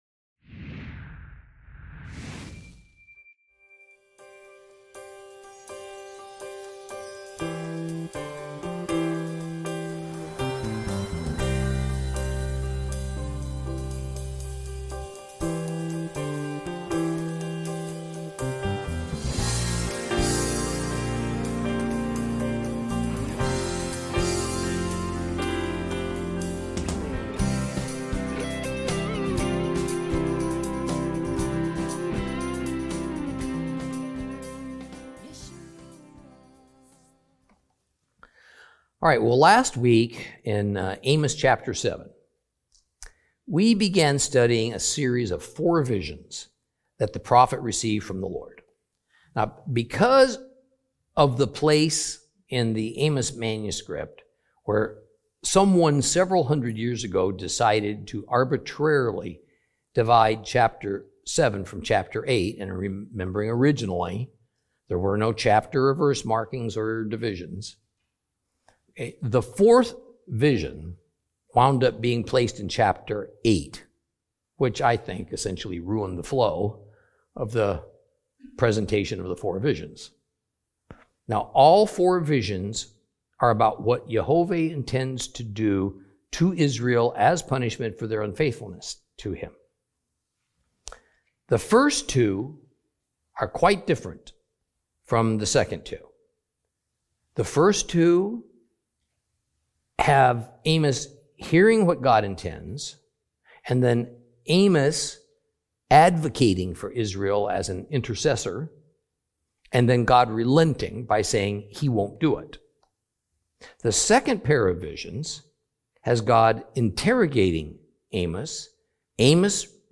Teaching from the book of Amos, Lesson 13 Chapter 8.